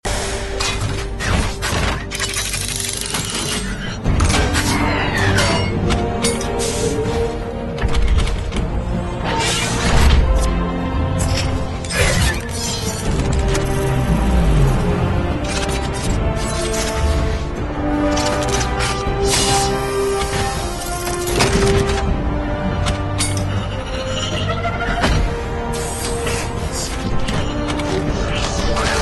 optimus-prime-transforming_24870.mp3